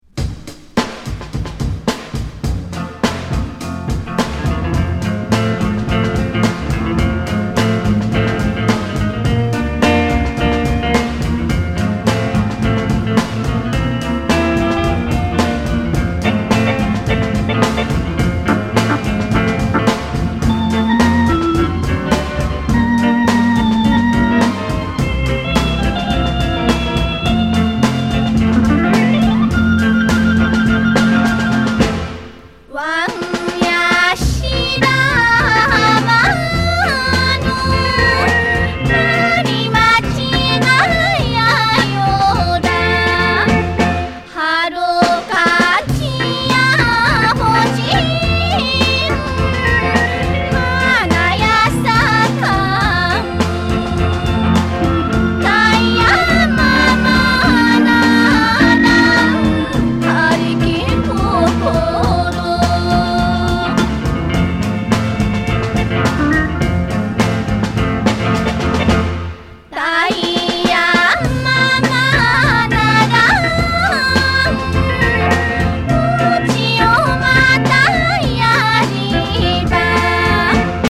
沖縄伝統音楽にロックやソウルのグルーヴを施した人気コンピレーション